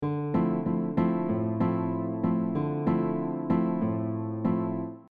This mellow and beautiful style uses syncopated rhythms with a fingerpicking style of playing.
We can also add more repeated 8th note rhythms like in the example below.
Bossa nova pattern - Adding more rhythm (variation)
Bossa-Nova-pattern-5.mp3